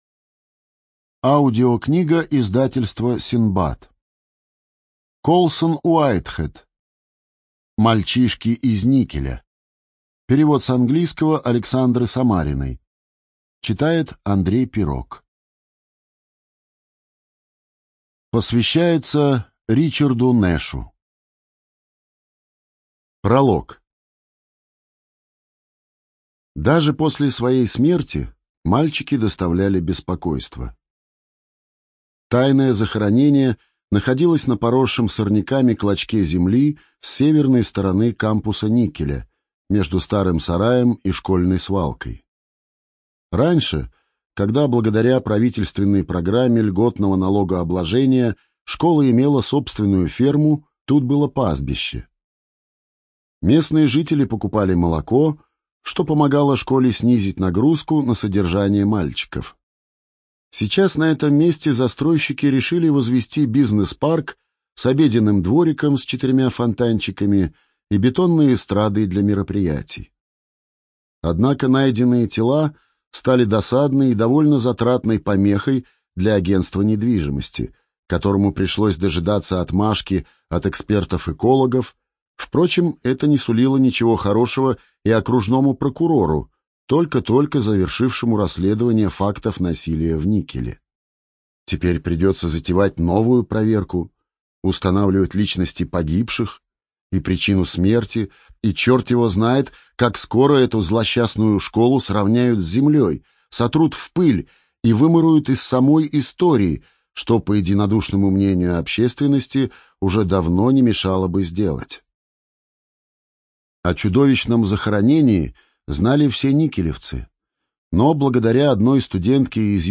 Студия звукозаписиСиндбад